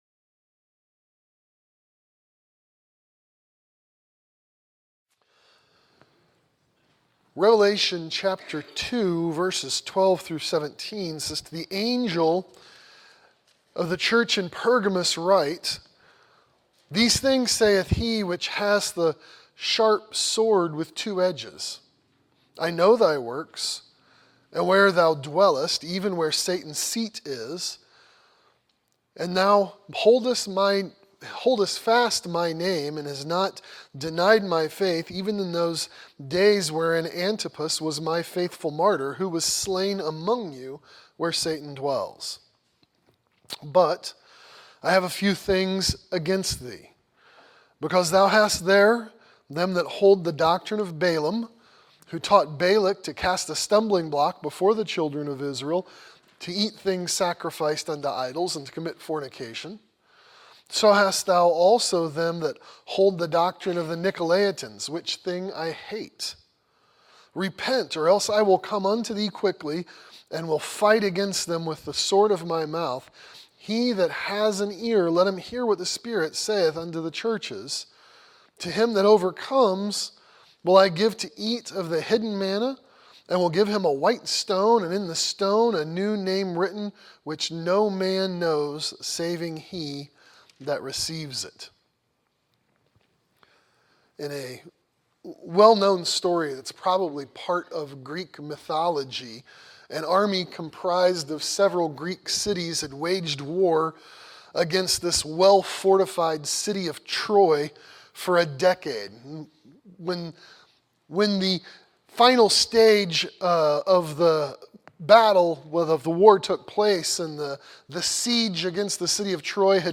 Condemning Compromise | SermonAudio Broadcaster is Live View the Live Stream Share this sermon Disabled by adblocker Copy URL Copied!